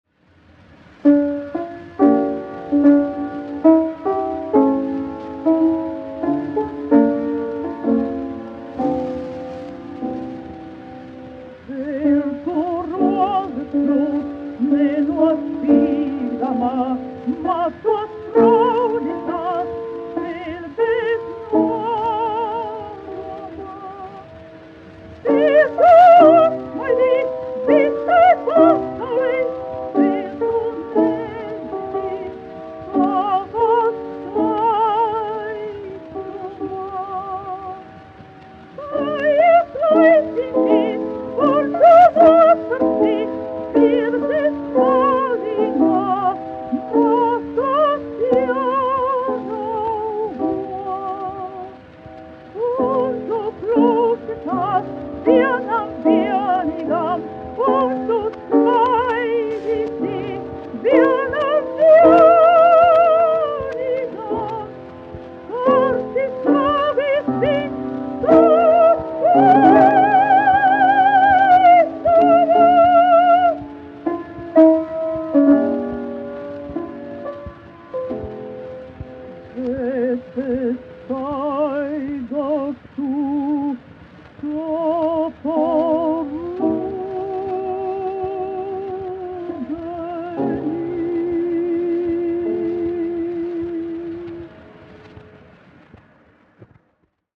1 skpl. : analogs, 78 apgr/min, mono ; 25 cm
Dziesmas (augsta balss) ar klavierēm
Latvijas vēsturiskie šellaka skaņuplašu ieraksti (Kolekcija)